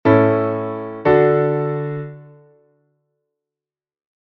Cadencia Auténtica Perfecta: os dous acordes están en estado fundamental
LA-DO-MI-LA; RE-RE-FA-LA (V-I)
cadencia_autentica_perfecta.mp3